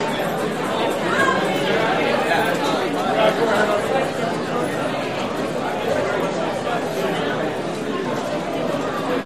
Crowd Indoor Hotel Lobby